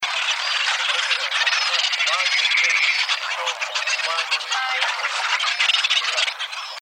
PETREL GIGANTE OSCURO
petrelgiganteoscuro.wav